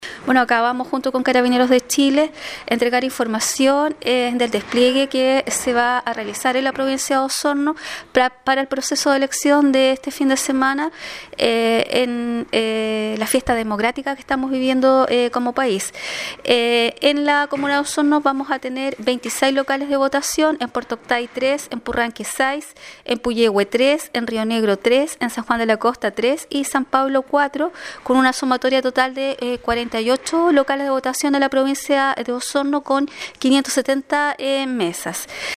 La delegada presidencial provincial de Osorno, Claudia Pailalef informó que serán 570 mesas distribuidas en 48 locales de votación en las siete comunas del territorio, más de la mitad de ellas en Osorno.